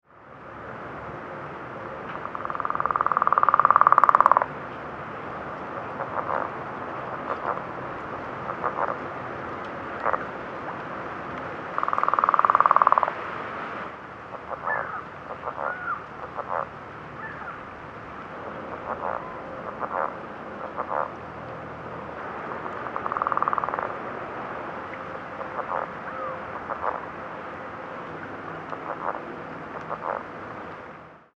The advertisement call of the Northern Leopard Frog is a moderately loud low gutteual snore-like rattle, which has been compared to a small motor boat engine.
The five sound files below were all recorded at the same pond in Grant County, Washington, shown in the three pictures below.
Sound This is a 31 second edited recording of a distant frog calling on a sunny afternoon in late April.